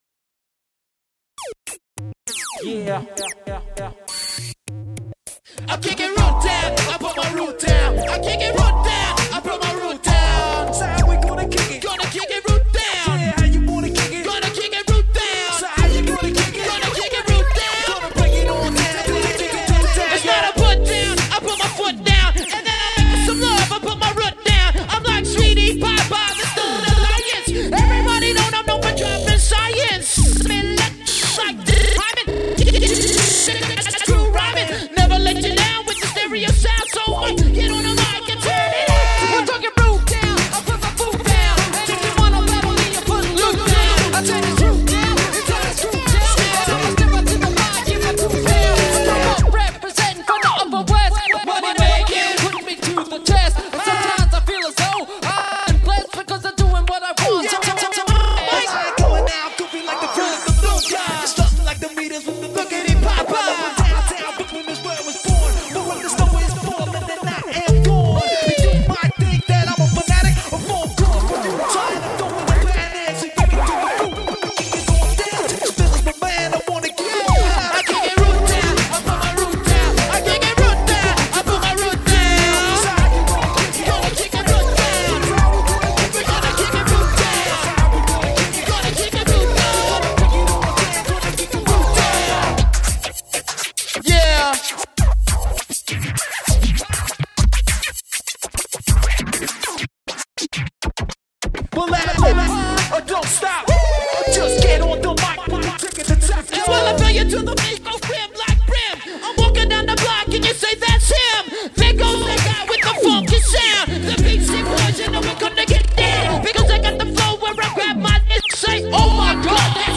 Remix: